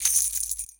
Closed Hats
Medicated Perc 1.wav